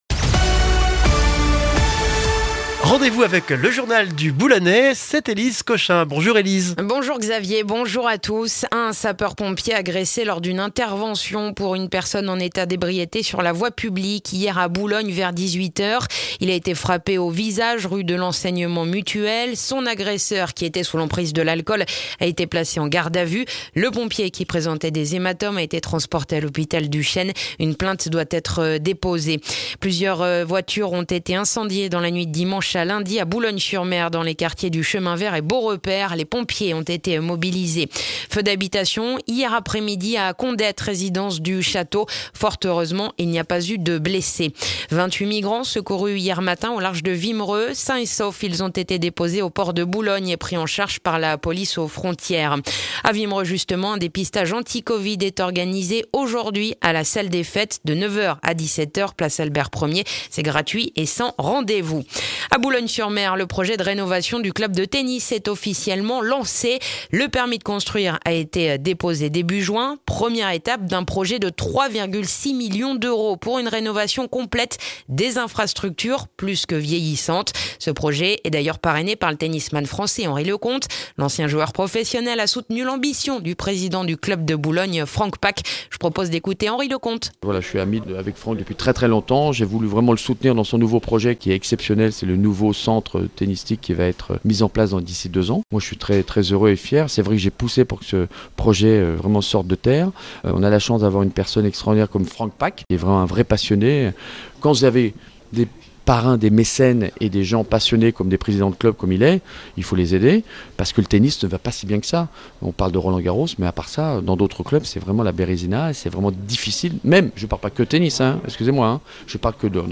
Le journal du mardi 29 juin dans le boulonnais